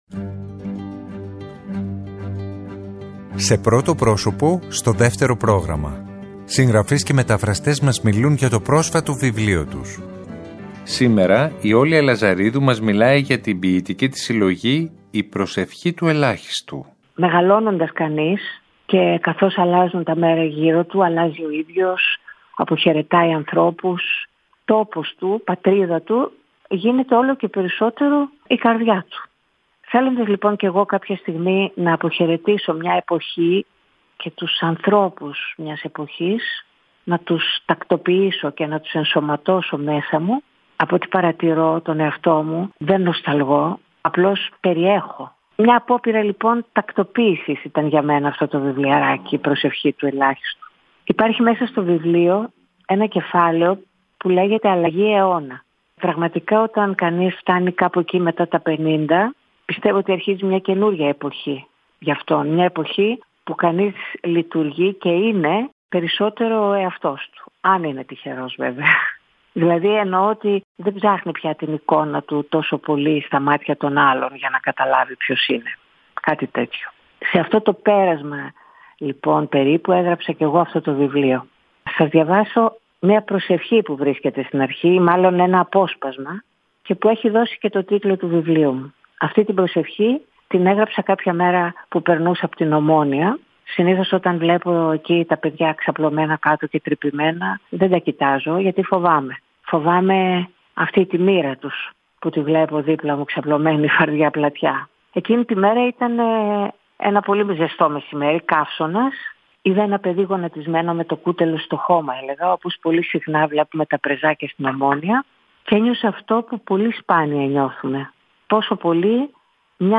Συγγραφείς και μεταφραστές μιλάνε
Σήμερα Τρίτη 07 Νοεμβρίου 2023 η Όλια Λαζαρίδου μας μιλάει για την ποιητική της συλλογή «Η προσευχή του ελάχιστου».